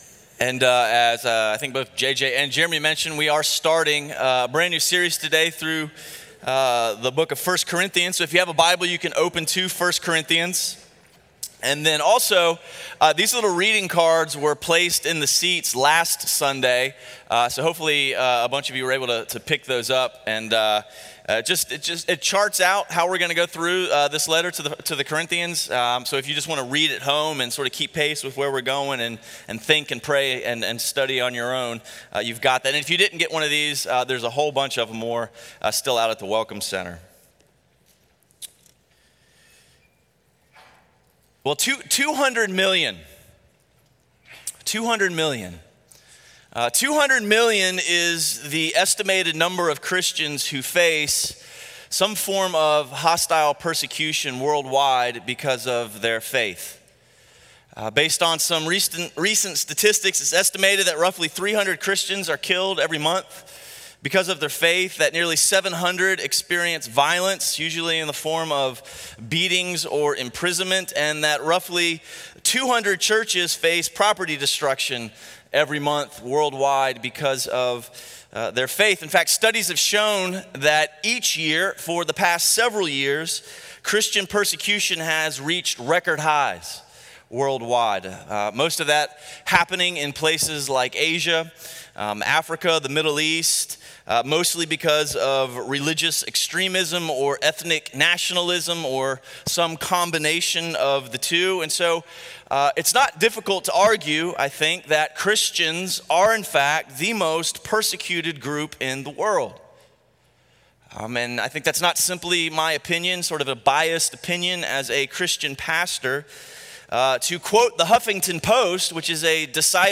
The opening sermon to our Called Out series on 1 Corinthians.**Due to technical difficulties the end of the sermon was not recorded.